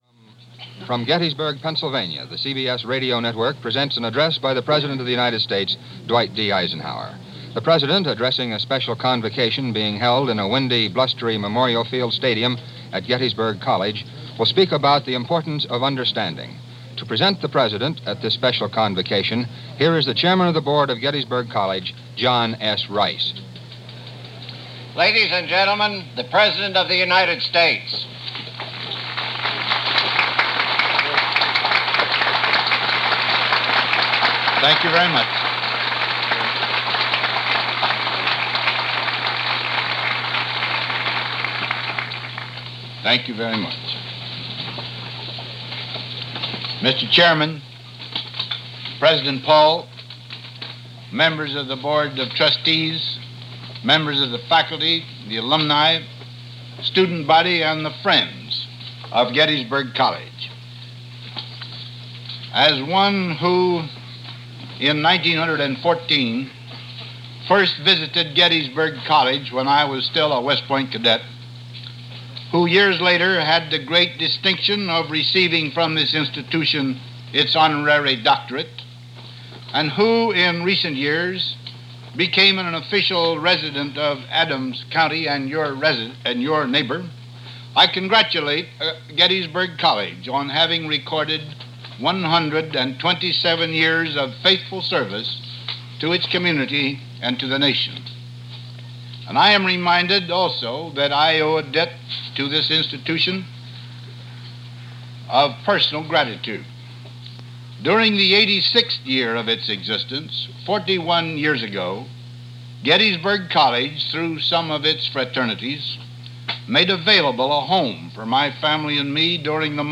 President Eisenhower, addressing students at Gettysburg College on April 4, 1959 discusses our Foreign relations and our role in Vietnam.
April 4, 1959 – President Eisenhower Addresses Students at Gettysburg College –